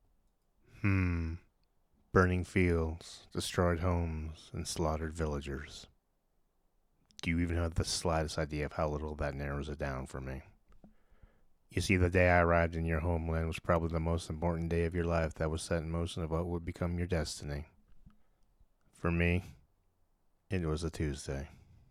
Male
Calm and collected with potential for sinister and sophisticated characters.
Video Games
Villain. Cold. Heartless. Calm
Words that describe my voice are textured, deep, authoritative.
0326Villain-Cold_Sophisticated.mp3